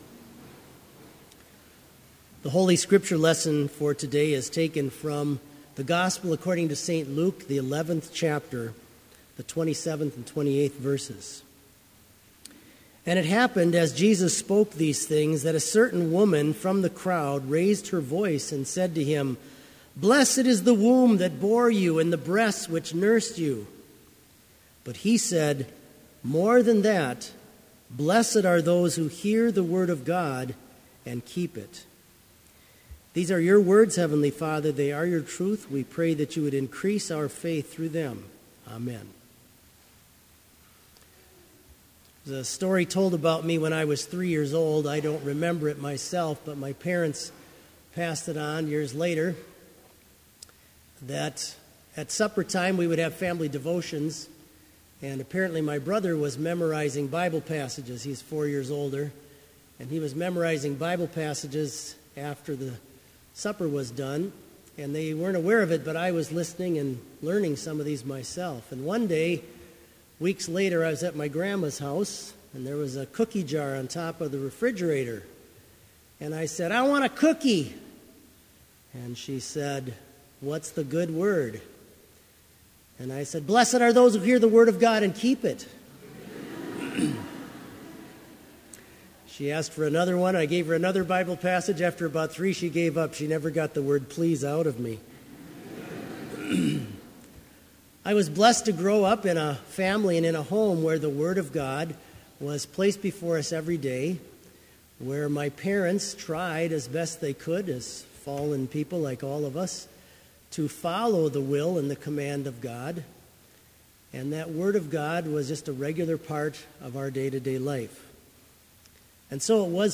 Complete Service
This Chapel Service was held in Trinity Chapel at Bethany Lutheran College on Tuesday, February 2, 2016, at 10 a.m. Page and hymn numbers are from the Evangelical Lutheran Hymnary.